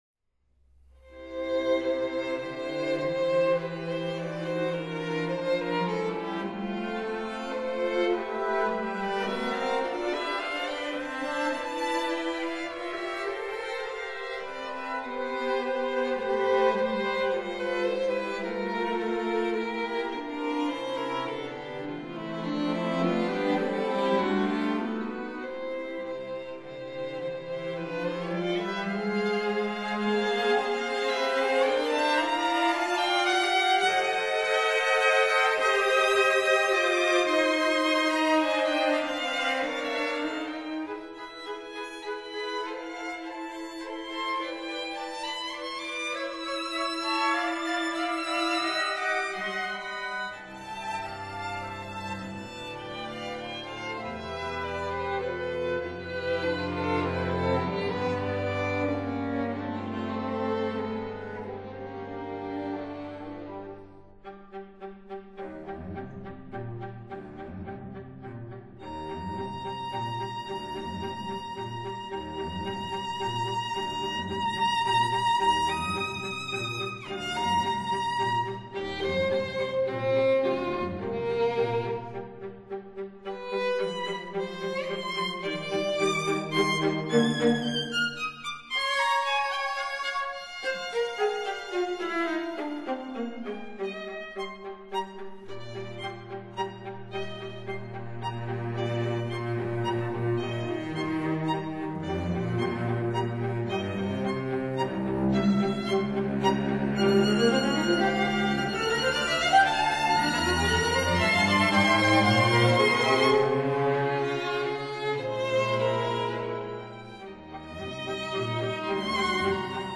分辑：CD 23-27  弦乐四重奏全集